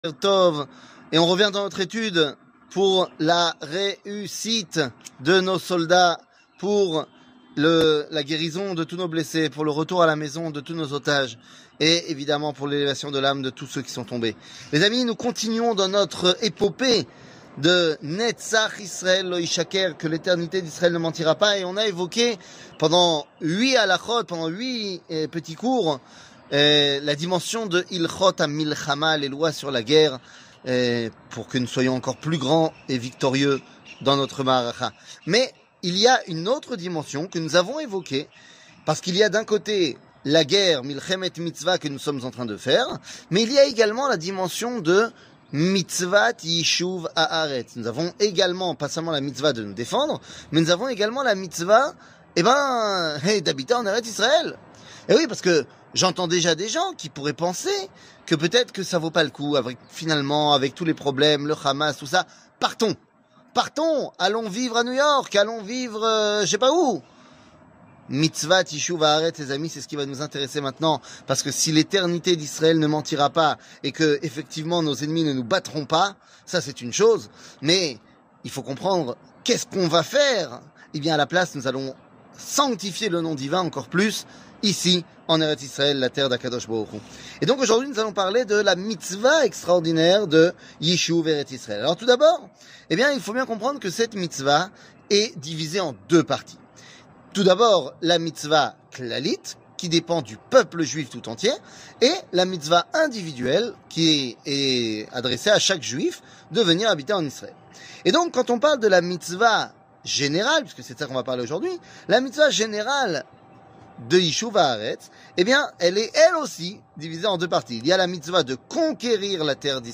L'éternité d'Israel ne mentira pas ! 9 00:07:57 L'éternité d'Israel ne mentira pas ! 9 שיעור מ 18 אוקטובר 2023 07MIN הורדה בקובץ אודיו MP3 (7.27 Mo) הורדה בקובץ וידאו MP4 (10.96 Mo) TAGS : שיעורים קצרים